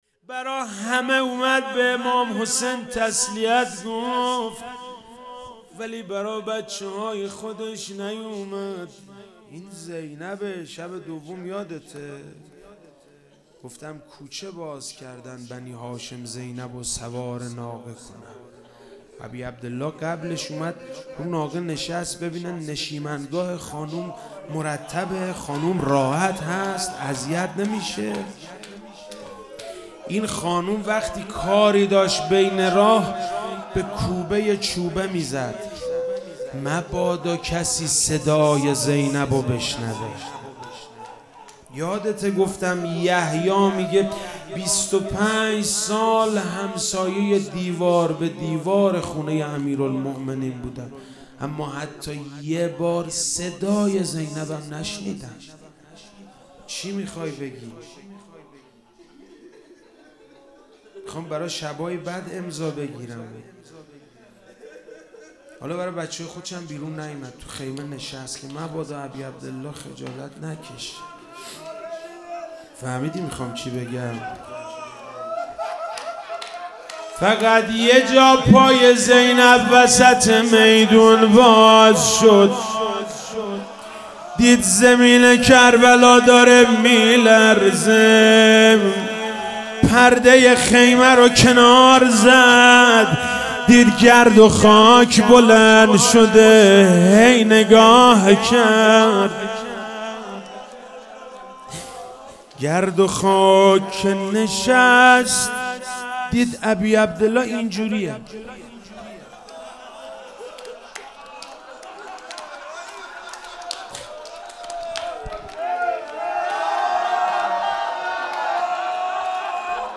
شب پنجم محرم 1399